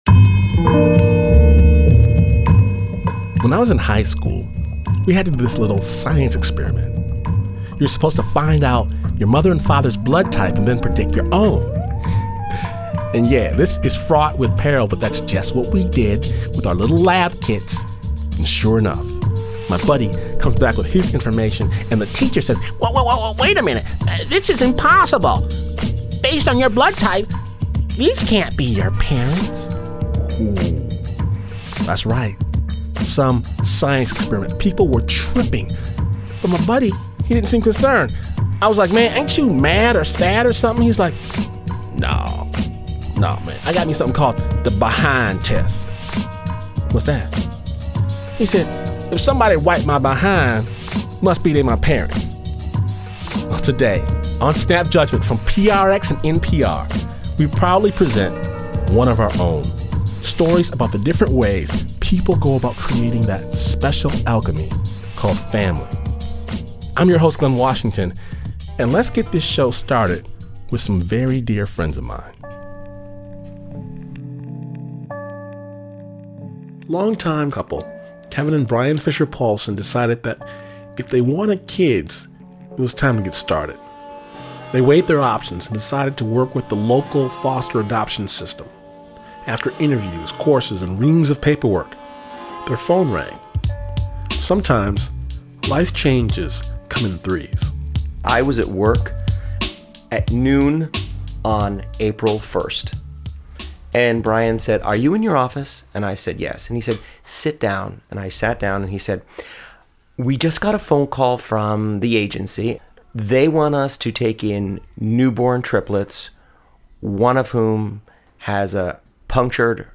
Snap Judgment (Storytelling, with a BEAT) mixes real stories with killer beats to produce cinematic, dramatic, kick-ass radio. Snap’s raw, musical brand of storytelling dares listeners to see the world through the eyes of another.